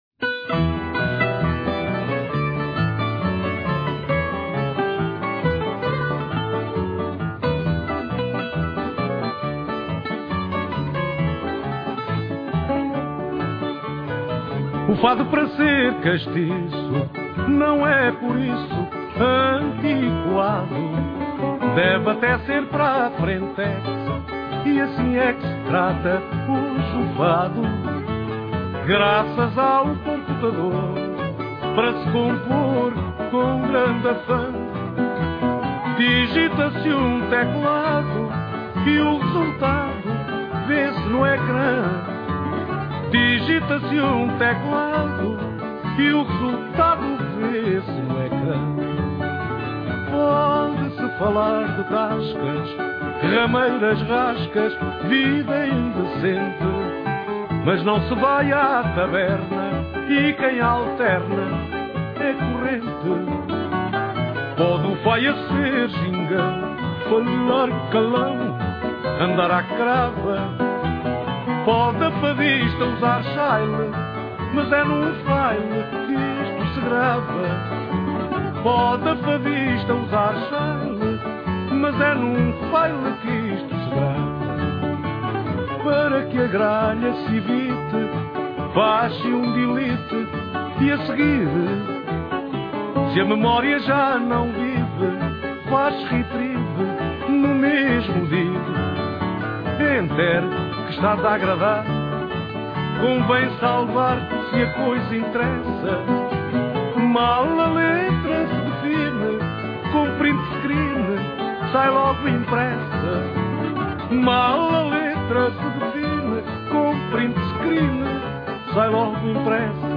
Guitarra
Viola
Viola Baixo